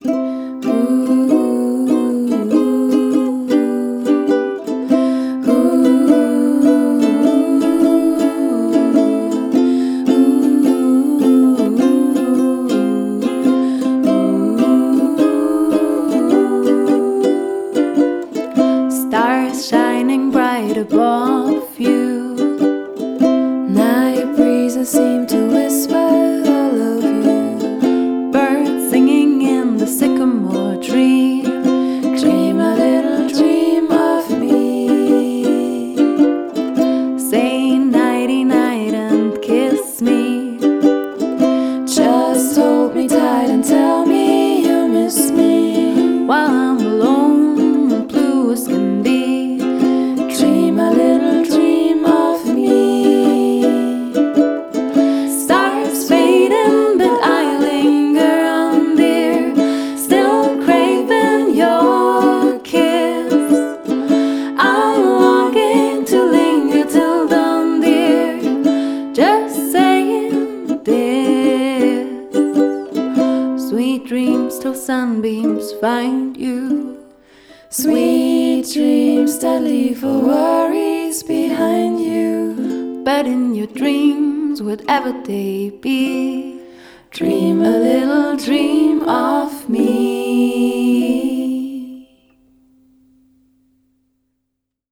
Trio
Acoustic-Trio für Trauung, Agape & eure Feierlichkeiten.
Indie & Folk